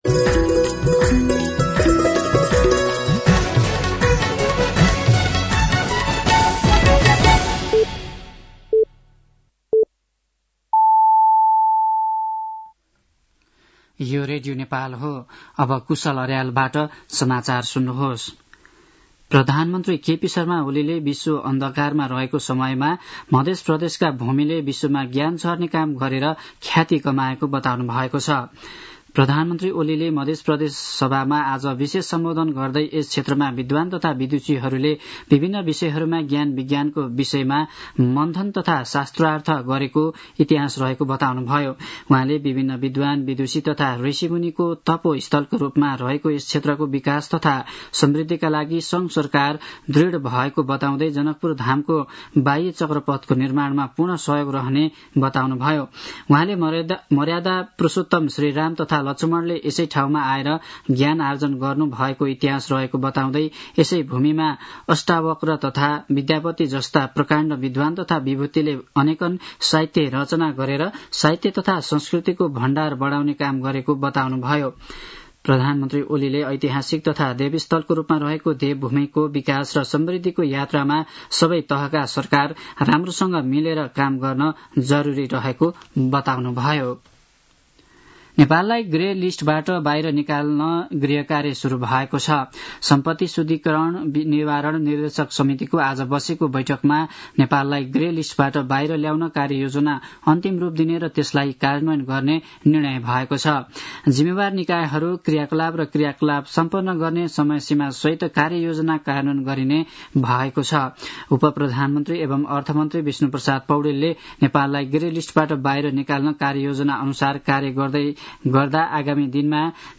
An online outlet of Nepal's national radio broadcaster
साँझ ५ बजेको नेपाली समाचार : २६ चैत , २०८१